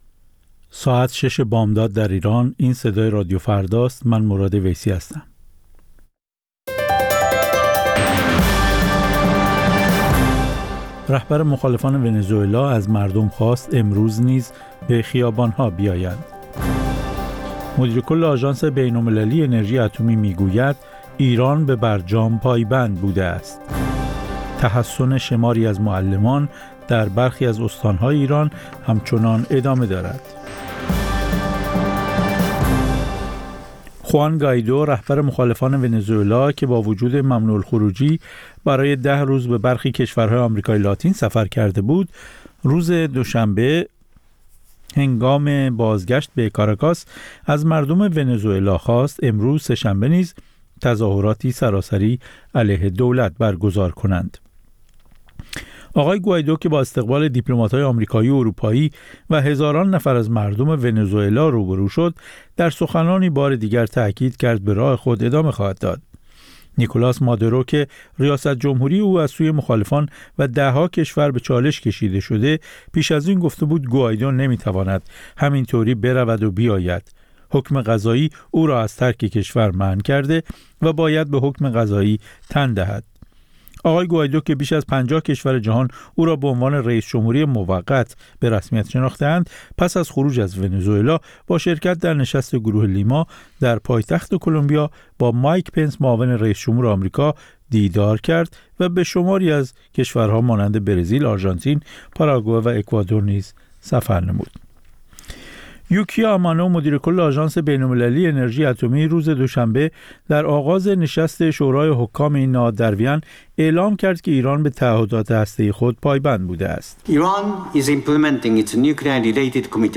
سرخط خبرها